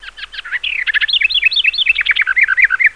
SOUND\BIRD4.WAV
1 channel